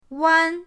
wan1.mp3